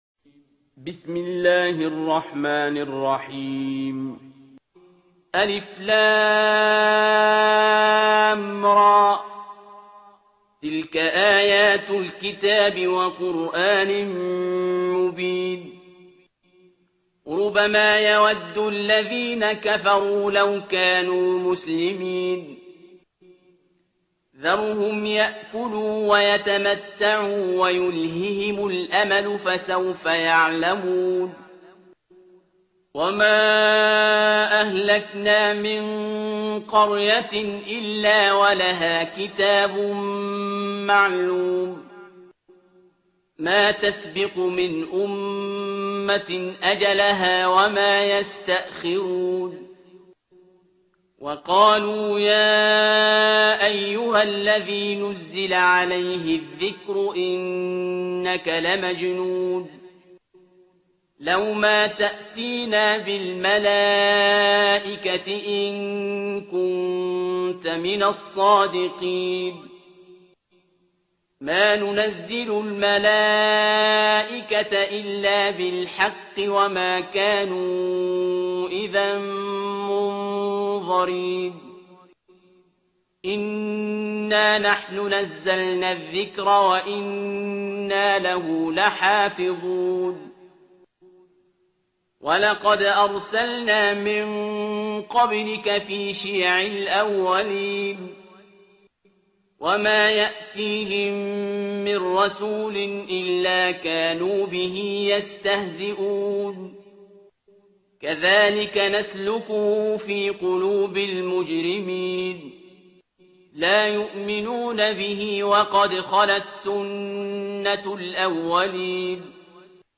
ترتیل سوره حجر با صدای عبدالباسط عبدالصمد